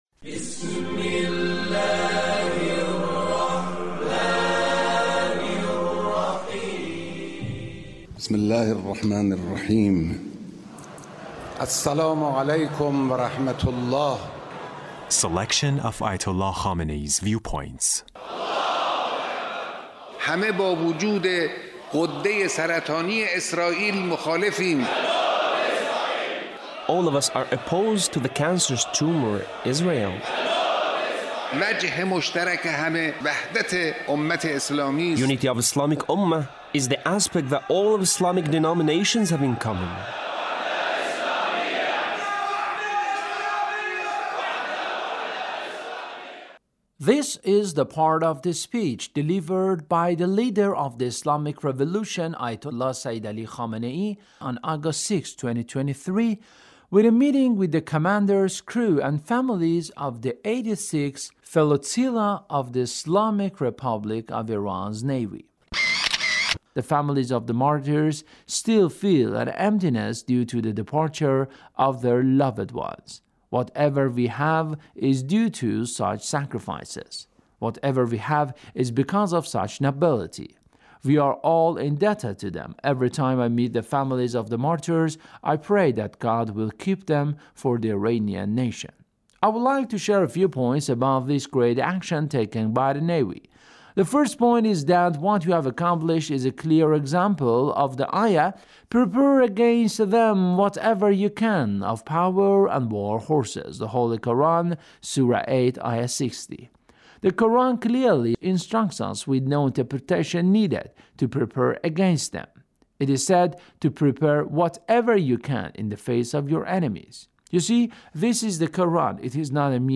Leader's Speech in a meeting with the commanders, crew, and families of the 86th Flotilla of the Islamic Republic of Iran Navy.